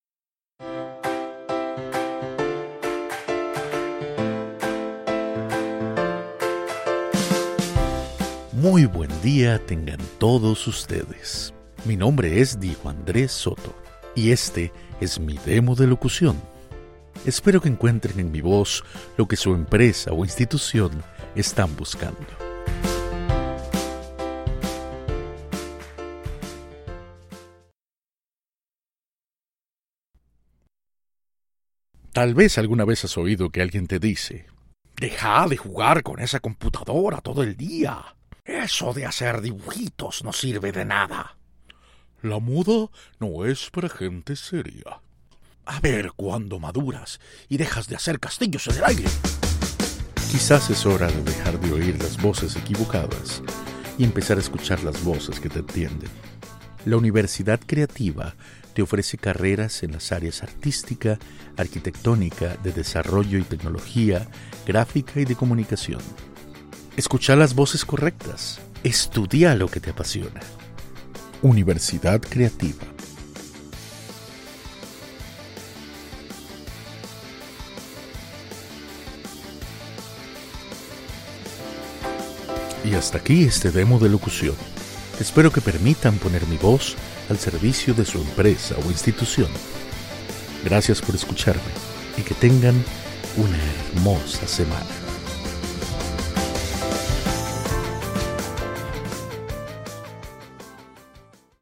Actor de voz y locutor comercial; especializado en la interpretación de personajes.
Sprechprobe: Werbung (Muttersprache):